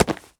A=PCM,F=96000,W=32,M=stereo
foley_object_grab_pickup_01.wav